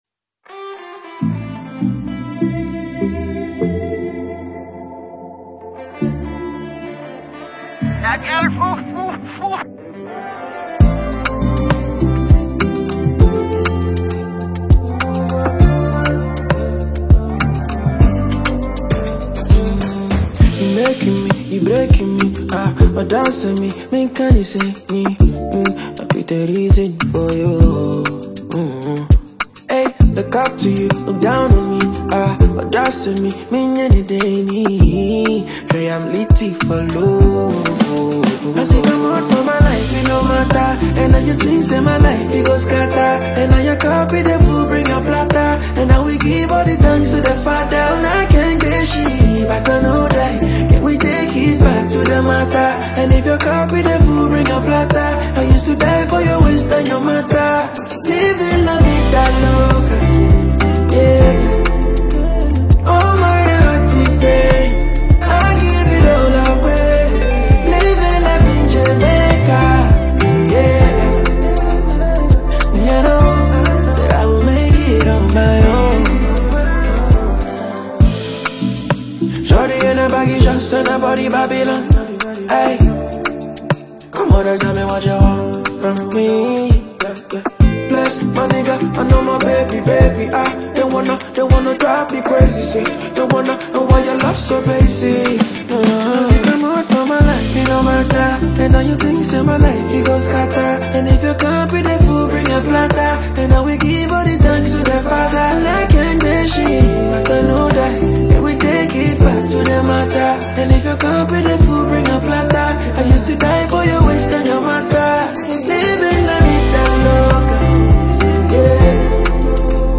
Ghana Music
minimalist and mysterious aesthetic
all delivered with his unmatched vocal silkiness.